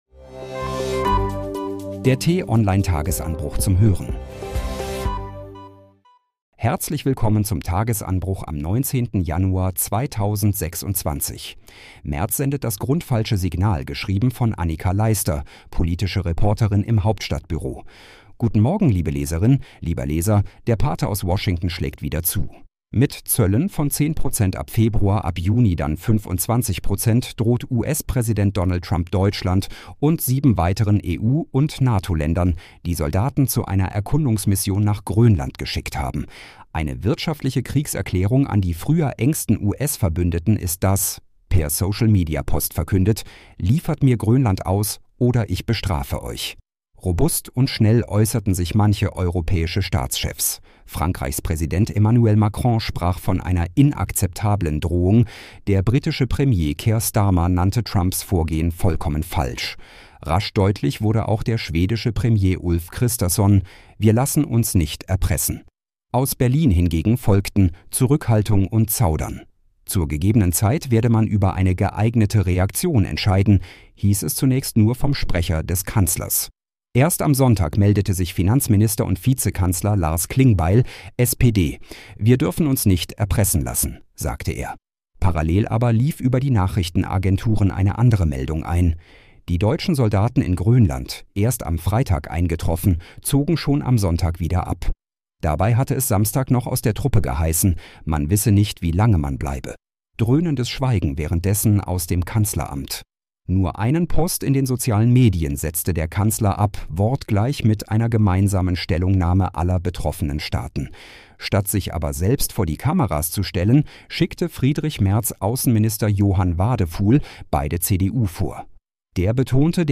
Den „Tagesanbruch“-Podcast gibt es immer montags bis freitags ab 6 Uhr zum Start in den Tag vorgelesen von einer freundlichen KI-Stimme – am Wochenende mit einer tiefgründigeren Diskussion.